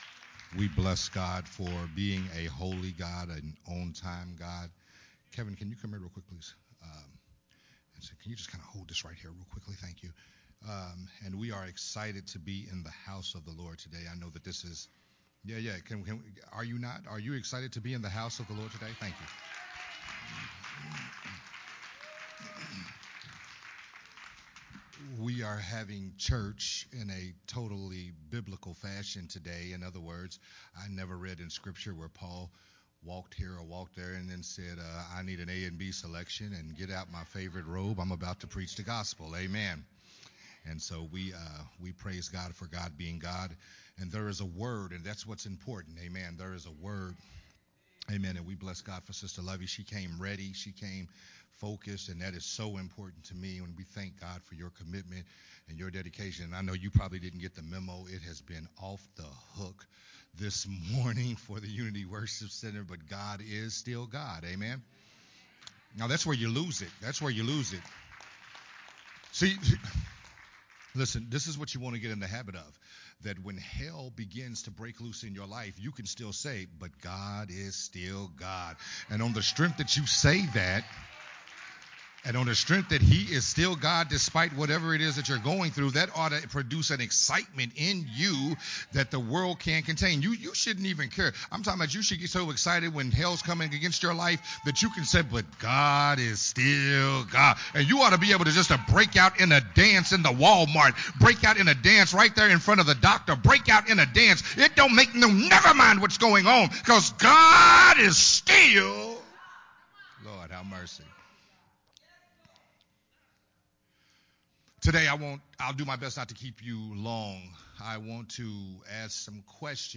a sermon
recorded at Unity Worship Center on December 18th